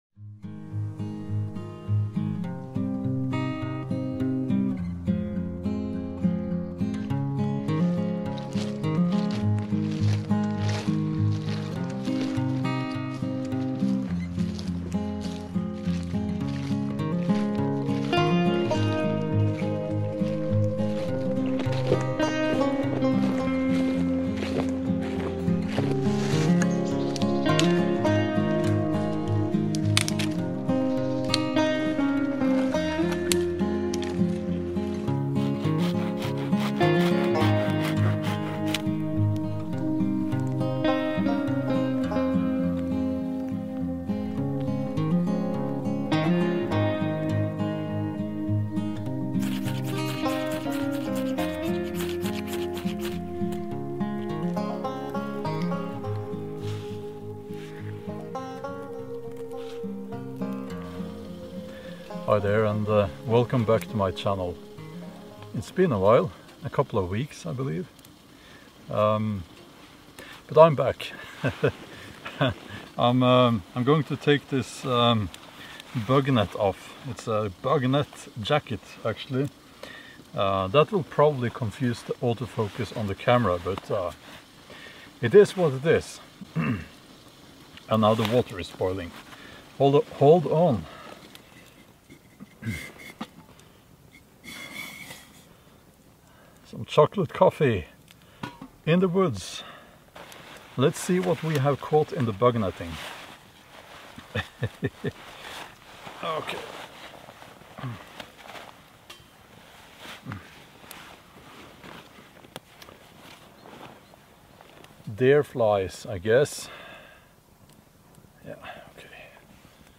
Where Do We Go From Here A Woodland Chat In Defiance of Megacities